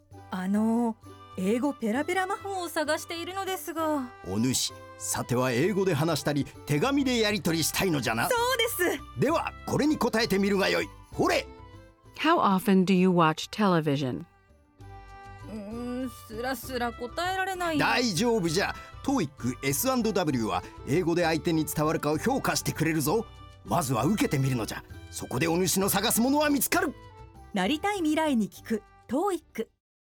英語を"聞いて"理解ができても、"話す"ことが苦手な人の背中を押せるような音声CMに。キャッチーさを出す演出として、魔法を求めるRPGゲームのようなストーリーで制作しました。